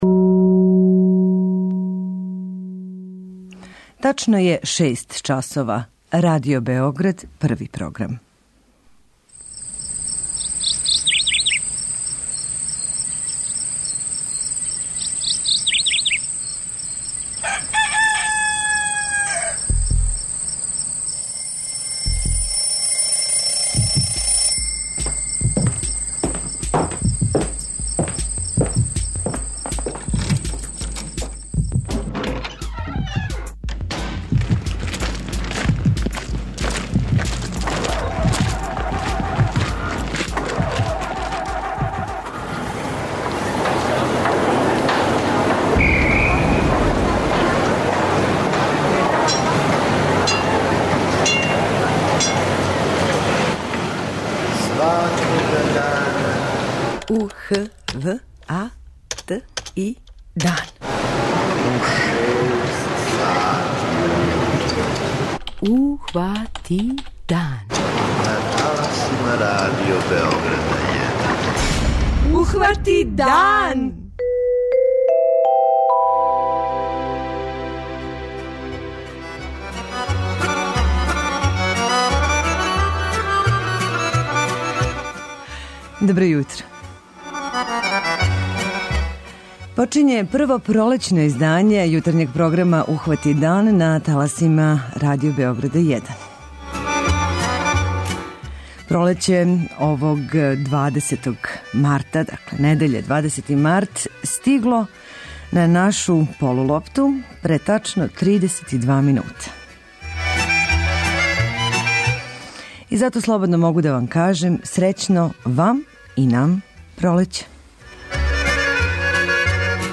- репортажу са поделе садница воћака и разговоре са воћарима у Сврљигу, који се у тој претежно сточарској, брдско-планинској општини, све више окрећу том виду пољопривреде;